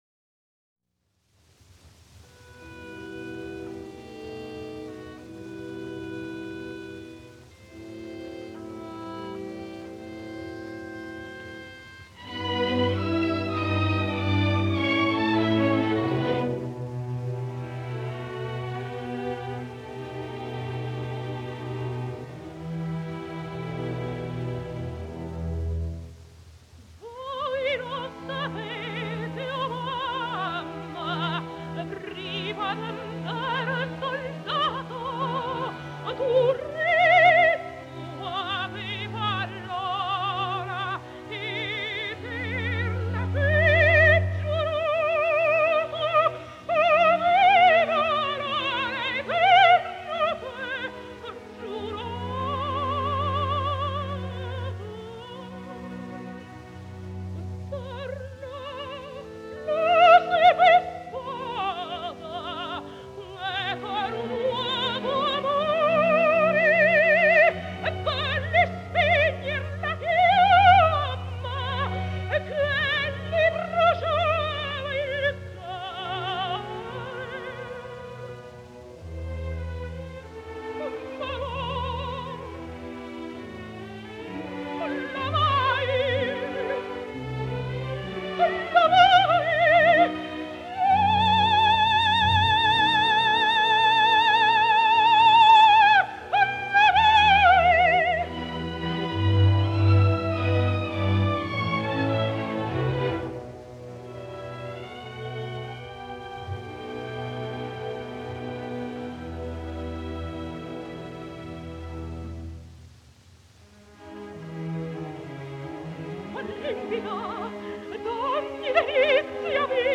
104 года со дня рождения итальянской певицы (сопрано) Лины Бруна Раза (Lina Bruna Rasa)